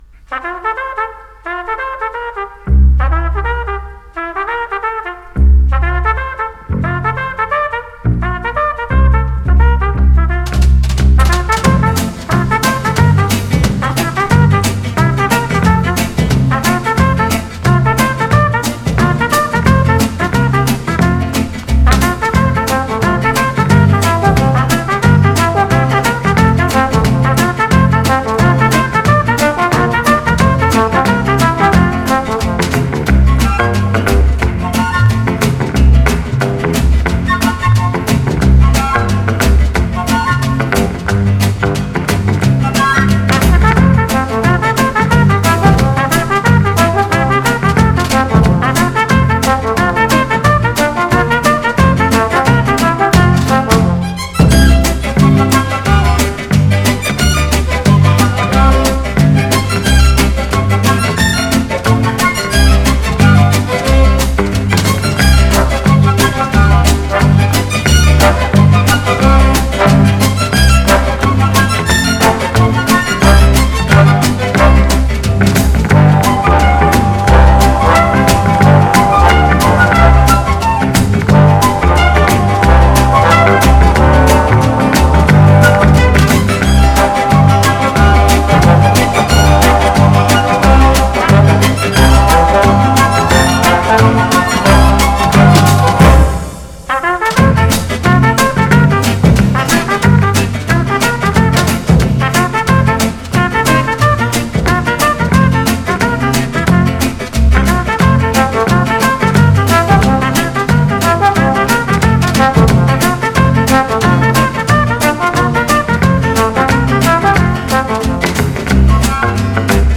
Genre: Jazz
Style: Easy Listening, Swing